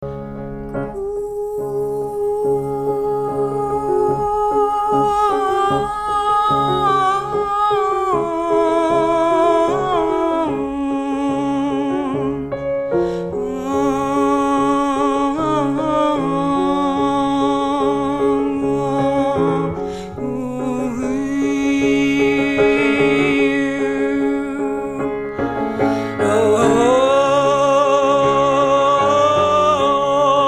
Poesie
Momentum-Aufnahmen